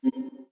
Index of /phonetones/unzipped/Motorola/AURA-R1/slider_tones
ui_basic_close.wav